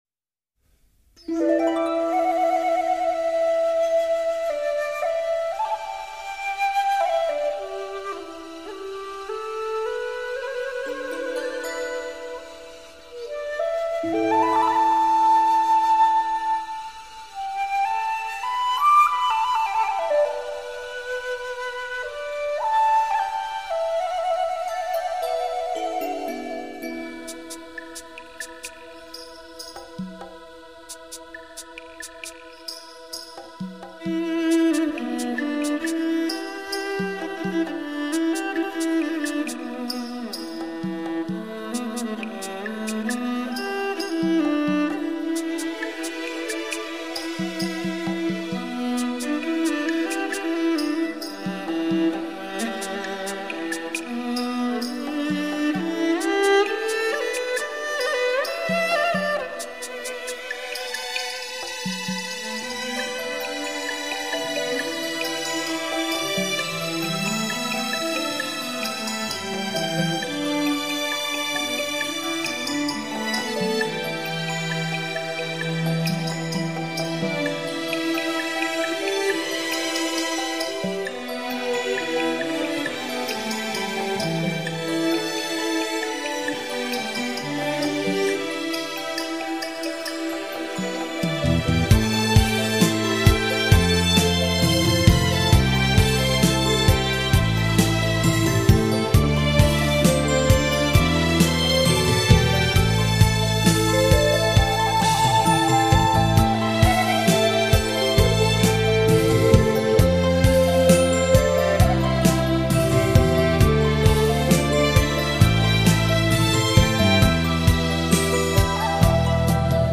乐队由中国民族乐器与西洋管弦乐器混合而成。
最新数码录音，音效发烧，音乐优美流畅。